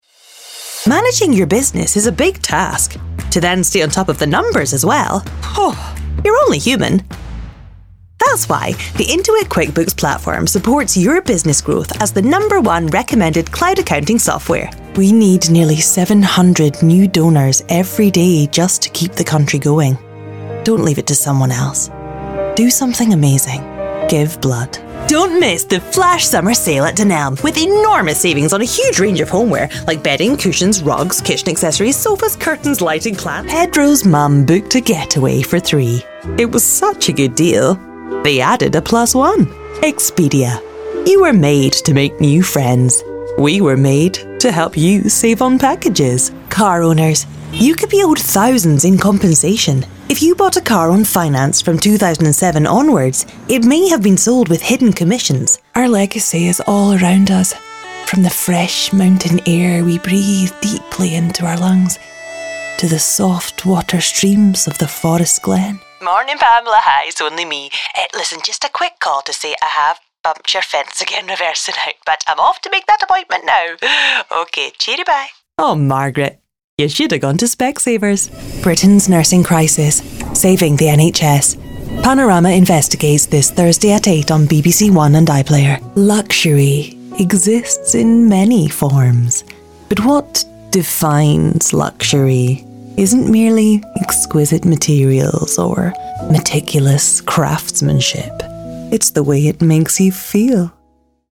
Voice Artists - Warm